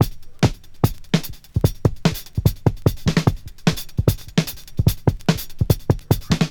Original creative-commons licensed sounds for DJ's and music producers, recorded with high quality studio microphones.
148 Bpm Modern Drum Loop A Key.wav
Free breakbeat sample - kick tuned to the A note.
148-bpm-modern-drum-loop-a-key-QjY.wav